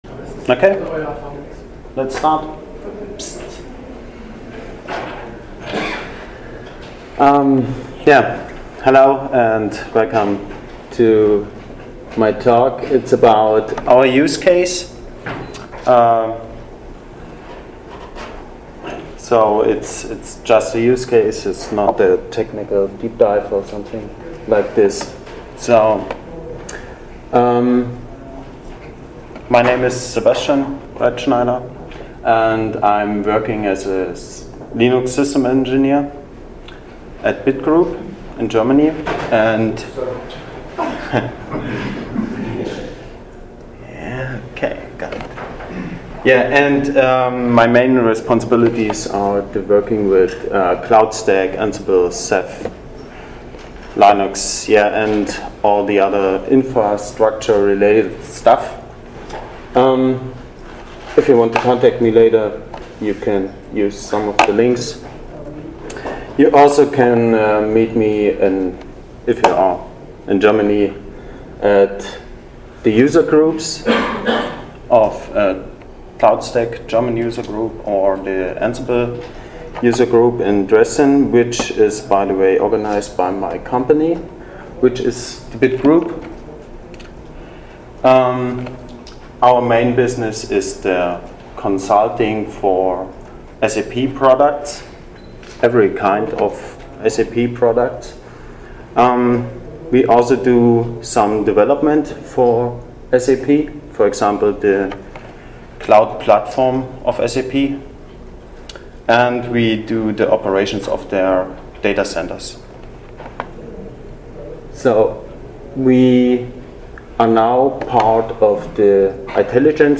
ApacheCon Miami 2017 – Usecase: Ansible + Cloudstack Automation